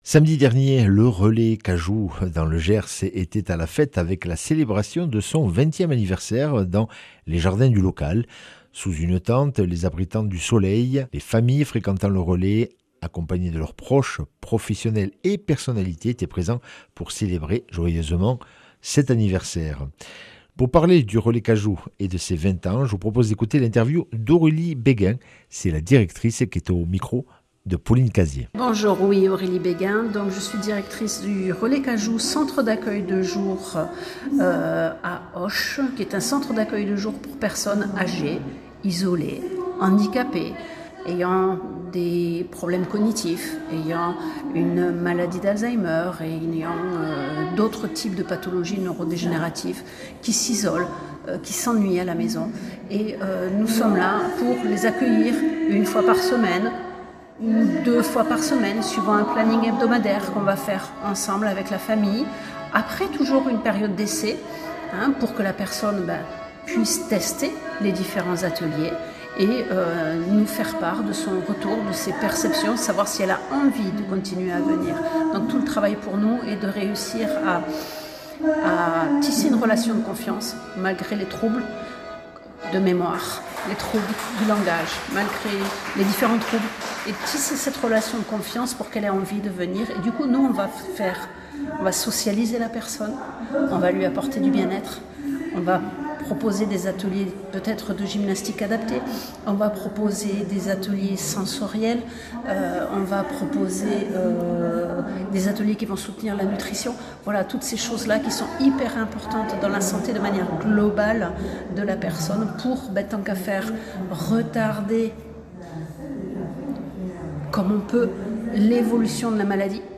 mercredi 24 septembre 2025 Interview et reportage Durée 10 min
Samedi dernier, le relais Cajou était à la fête avec la célébration de son vingtième anniversaire dans les jardins du local.
Un texte à la fois drôle et touchant qui a été très applaudi. Puis est venu le temps des chansons, accompagnées à l’accordéon.